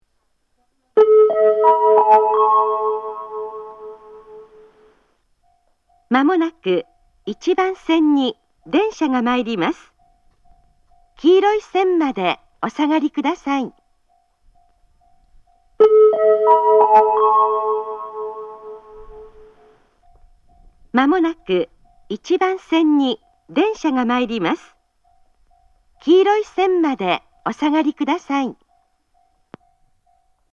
仙石旧型（女性）
接近放送
仙石旧型女性の接近放送です。同じ内容を2度繰り返します。